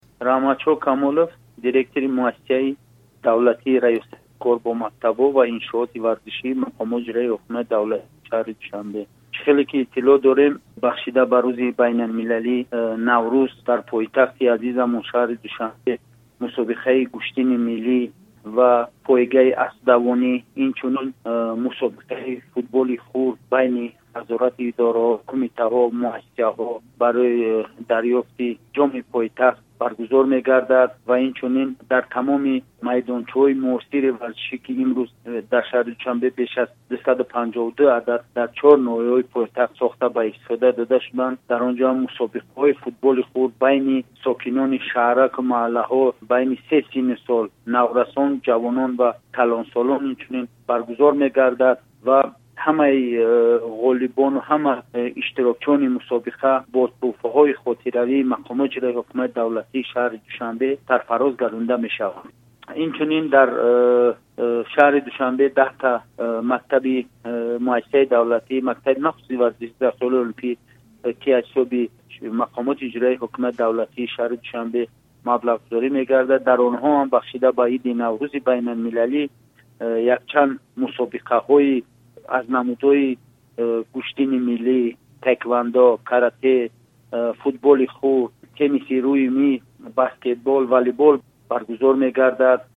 Гуфтугӯ